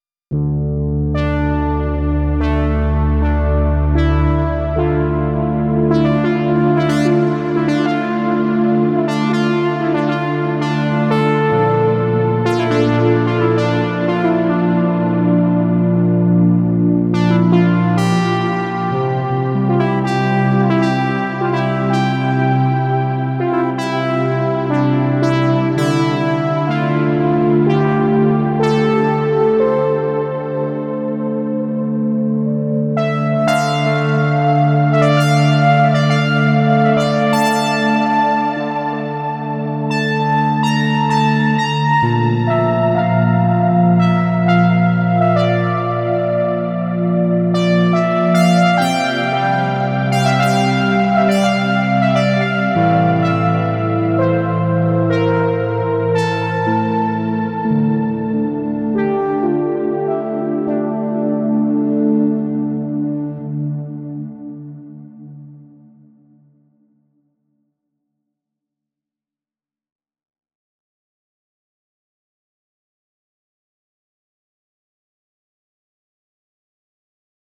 Der Vintage-Charakter rührt hier auch viel daher, dass es per Hand gespielt ist, mit allem was dazugehört.
Die Spielweise ist schon auch für diesen typischen "Vintage"Klang entscheidend.